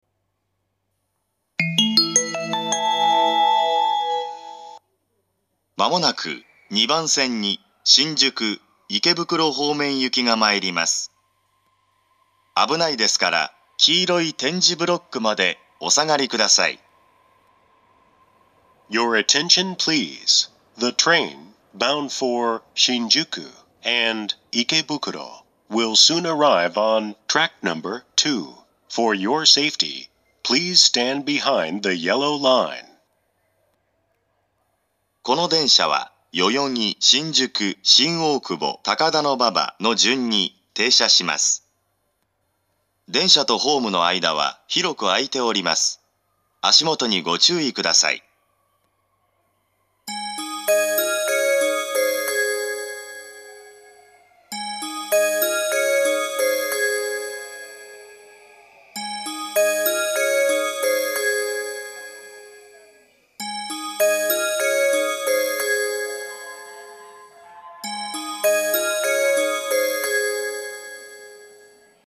２番線接近放送
１番線に比べると混雑時でも２コーラス目には入りにくいです。
また、利用客が非常に多いので、収録環境が大変悪いです。
harajuku2bansen-sekkin4.mp3